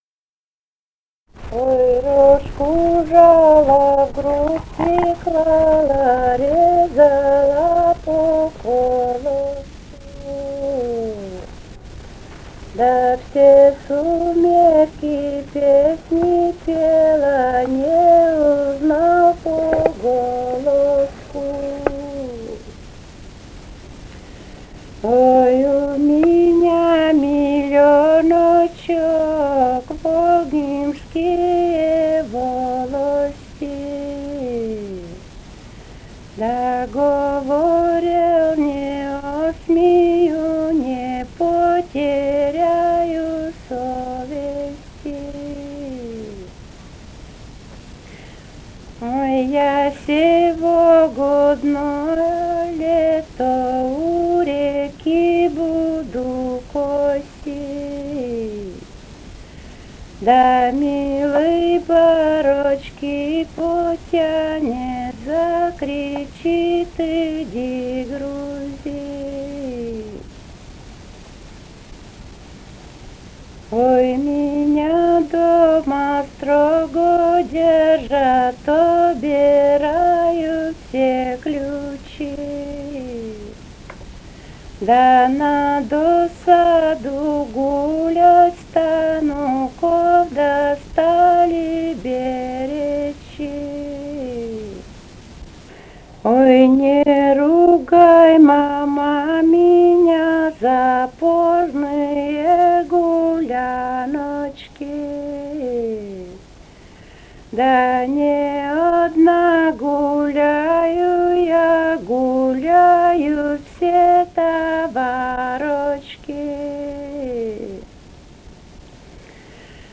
Живые голоса прошлого 166. «Ой, рожку жала» (качельные частушки).